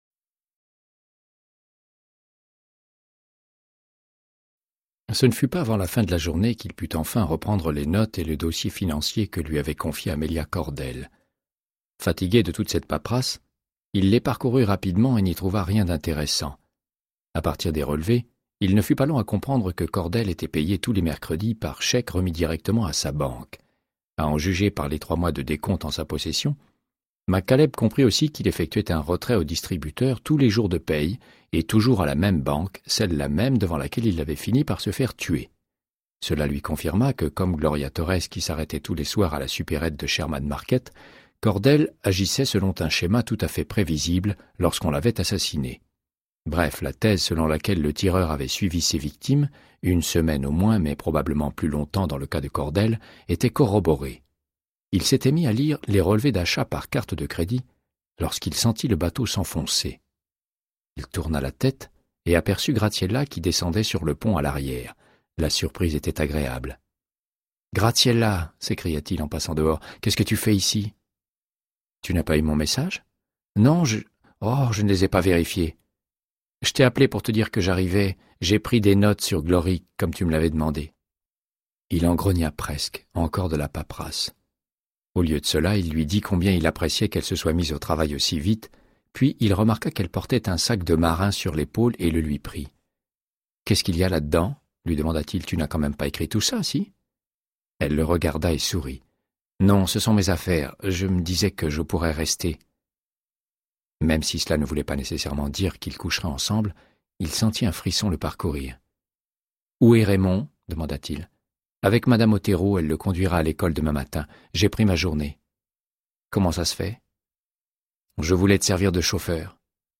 Audiobook = Créance de sang, de Michael Connelly - 105